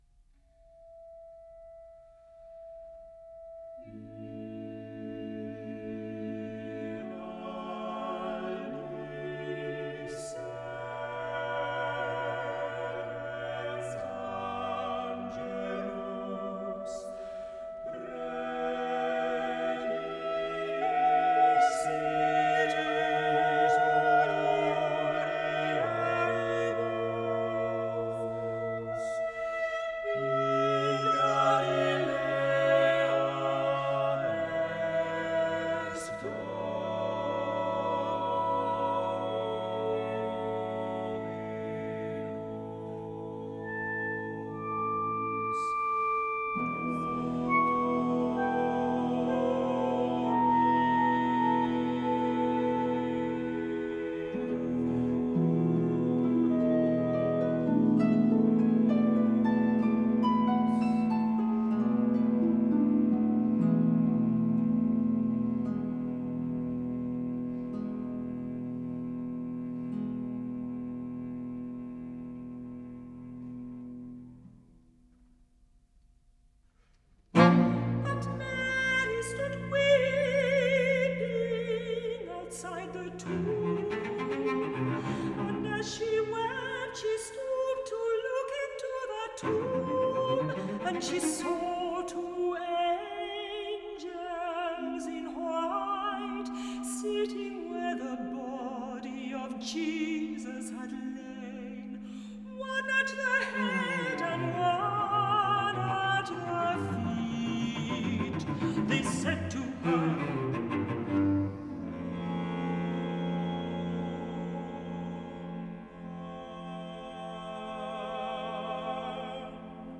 Lead vocal by tenor